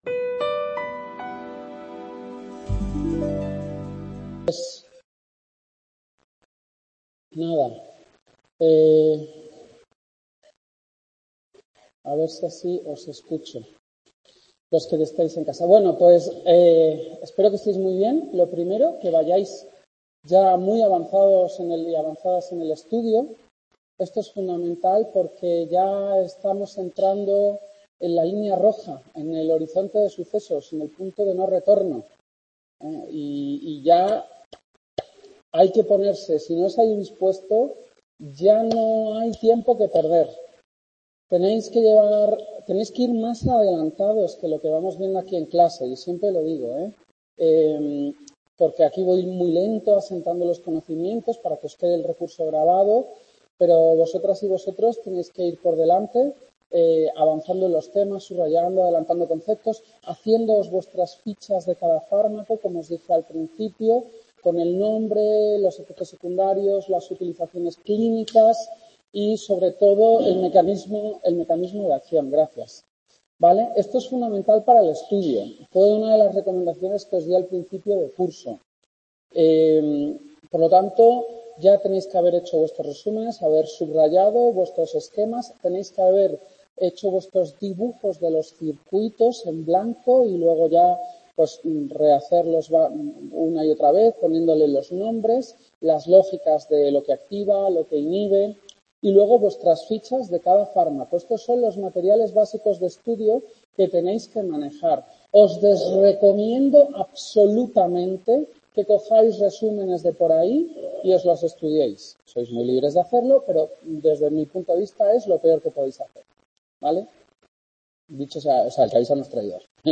Tema 2 segunda clase | Repositorio Digital